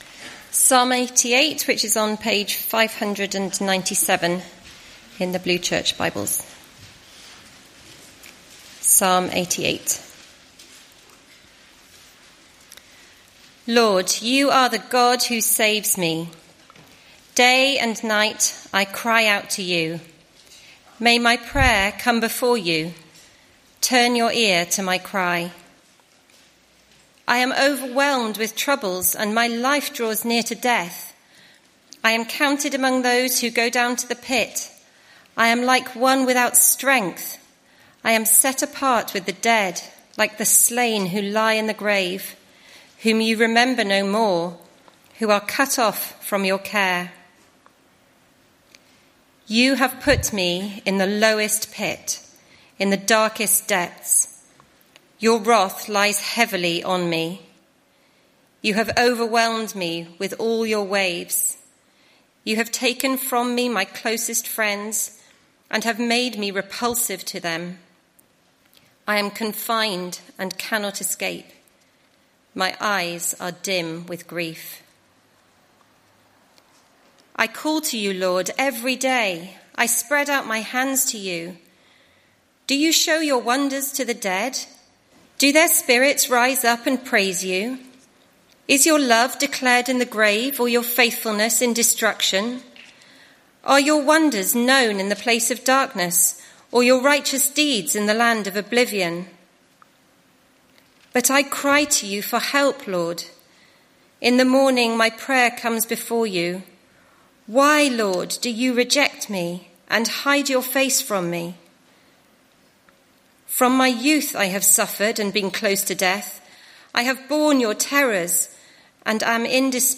Psalm 88; 30 March 2025, Evening Service. Sermon Series: Prayer and Praise - Psalms https